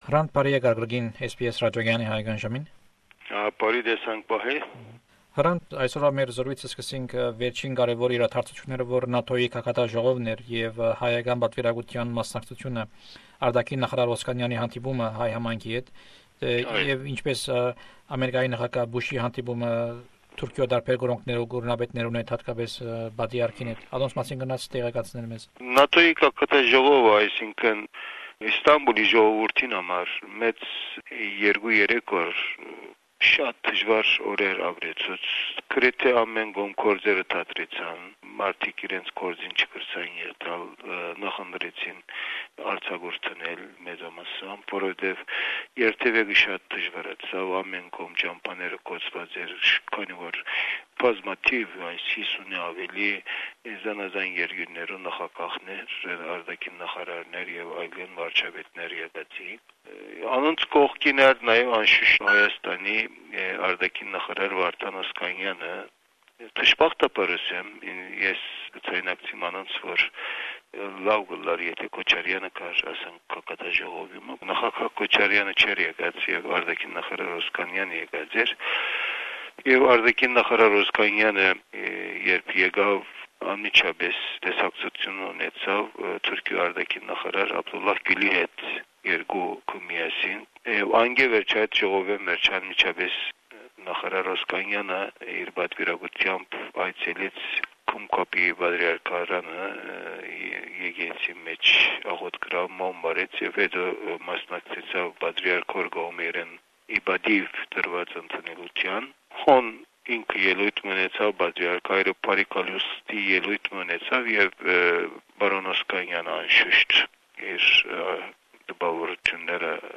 In memory of the 10th anniversary of the assassination of Hrant Dink, we bring you the interviews he gave to SBS Radios Armenian program.